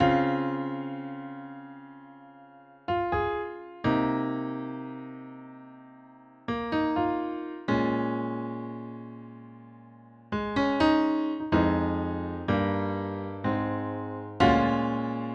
Steinway Piano 125 bpm.wav